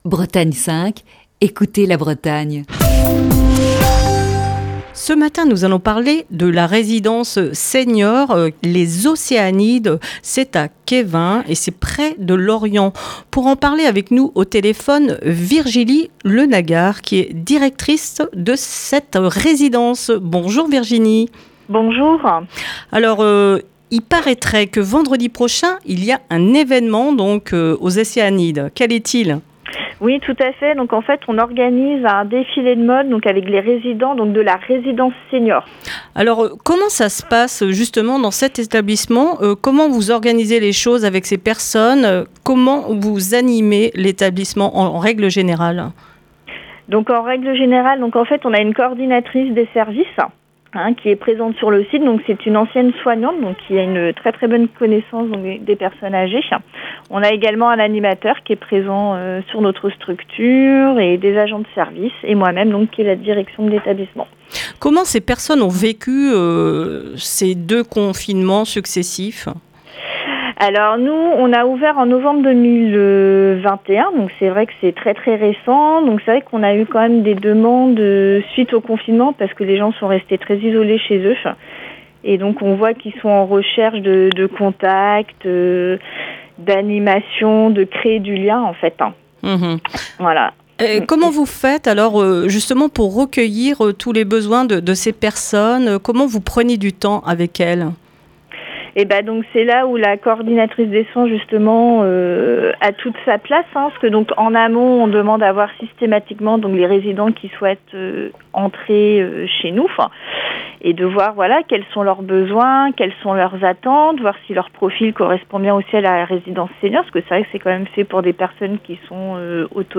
Ce mercredi, dans le coup de fil du matin,